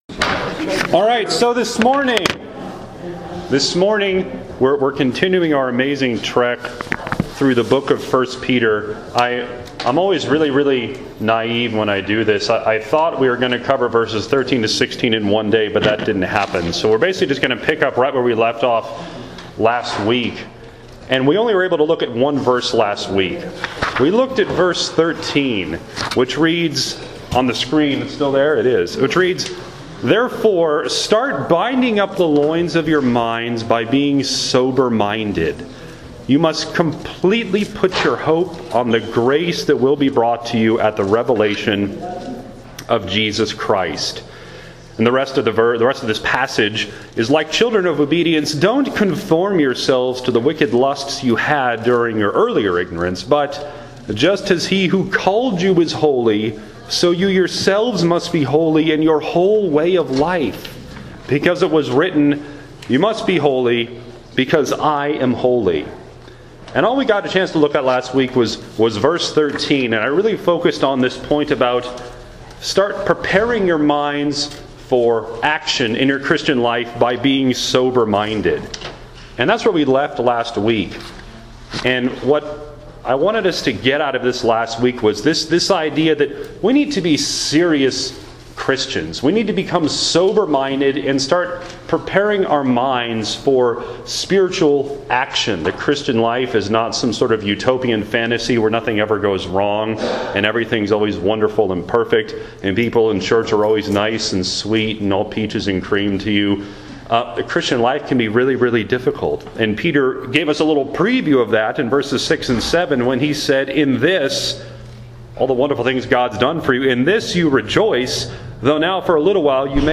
We talked about that in Sunday School this past week.
Behold, the audio for this past Sunday’s lesson: